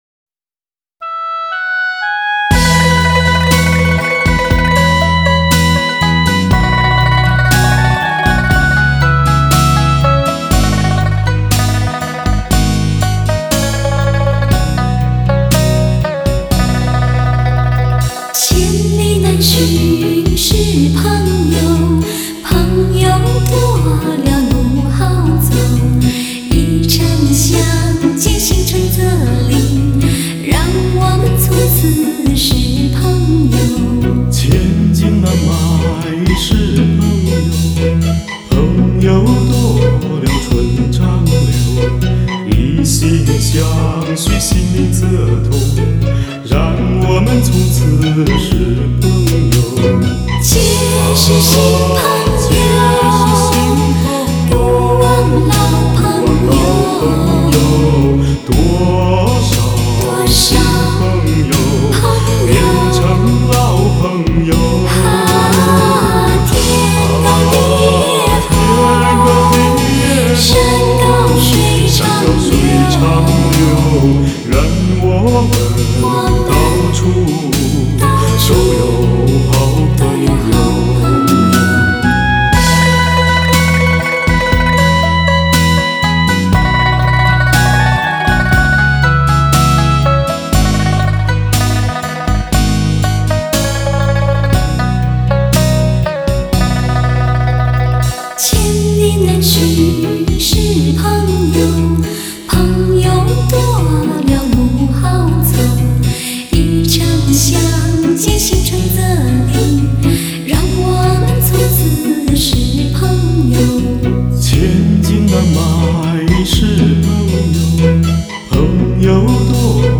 Ps：在线试听为压缩音质节选